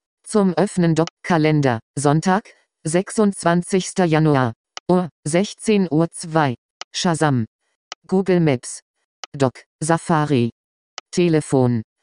Screen-Recording-App-Auswahl-geschnitten-1-1.mp3